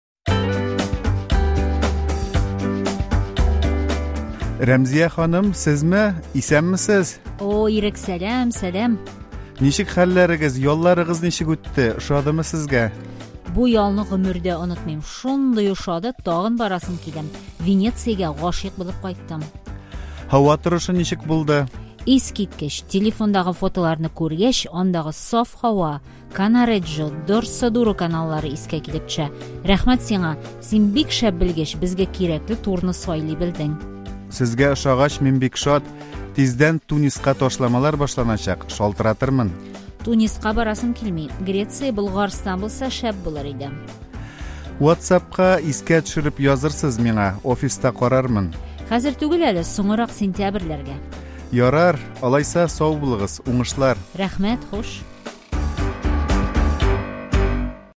ДИАЛОГ: ОНЫТЫЛМАСЛЫК БУЛДЫ
Кибеттә туроператор белән клиент очраша.